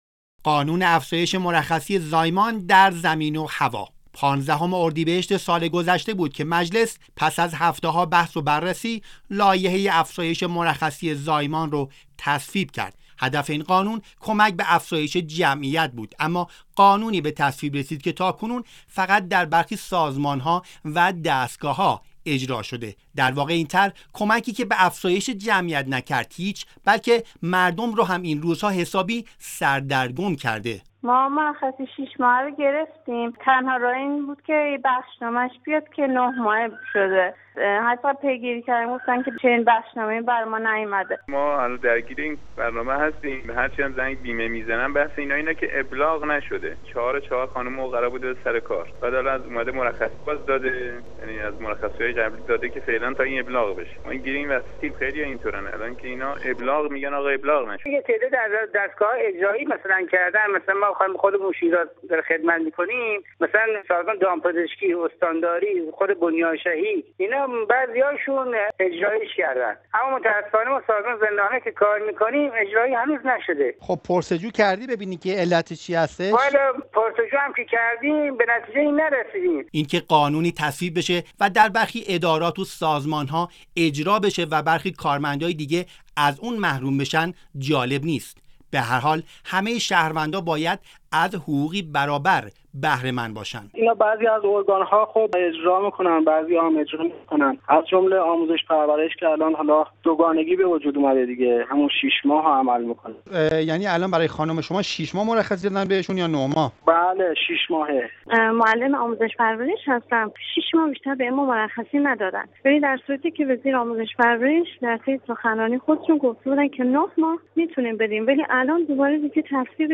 گزارش "شنیدنی" از مرخصی زایمان زنان؛ قانونی برای اجرا نشدن - تسنیم